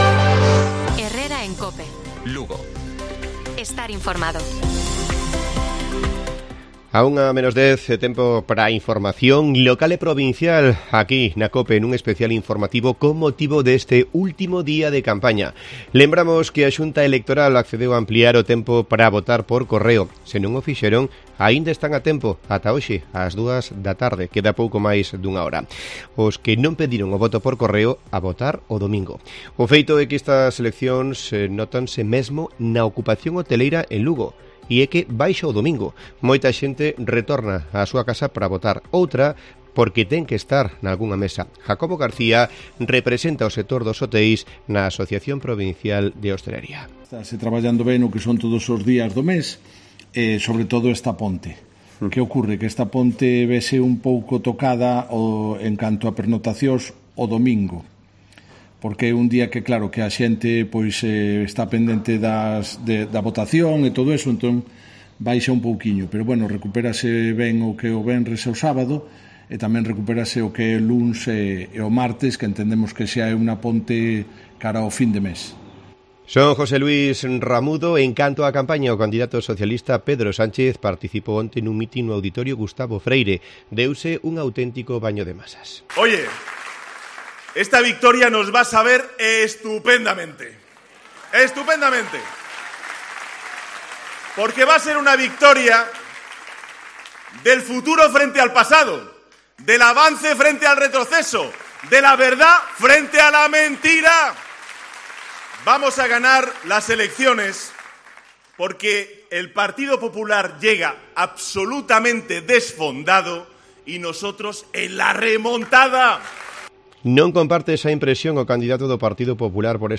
INFORMATIVO PROVINCIAL DE COPE LUGO. 12:50 HORAS. 21 DE JULIO